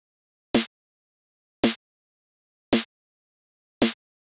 35 Snare.wav